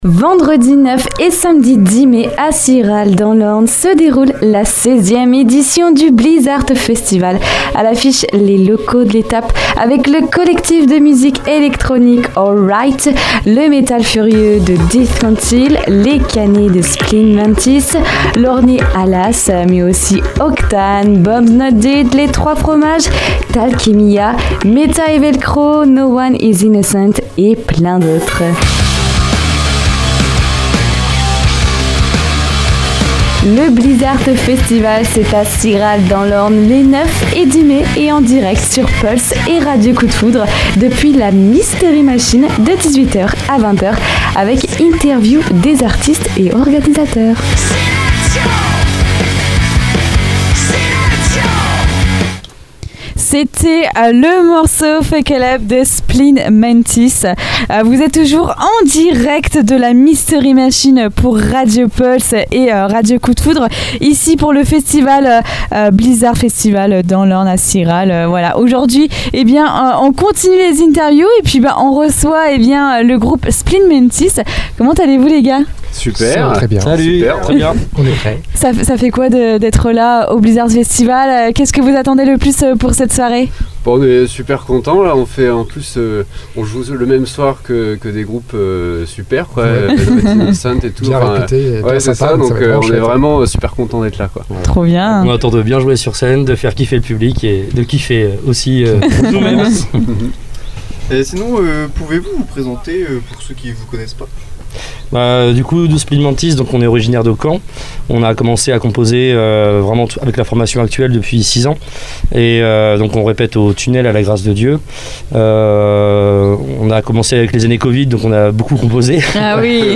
Dans notre podcast exclusif, enregistré en direct du festival, nous plongeons au cœur de l'univers de Spleen Mantis. Les membres du groupe partagent avec nous leur parcours, leurs inspirations et les coulisses de leur création musicale. Ils évoquent également leur dernier album, "One. Five", sorti en avril 2023, qui illustre parfaitement leur identité sonore singulière .